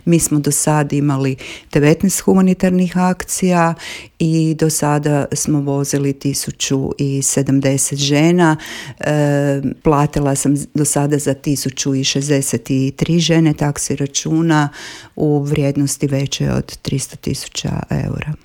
gostovala je u Intervjuu Media servisa u kojem je govorila o svemu što stoji iza ove prestižne nagrade te najpoznatijim projektima udruge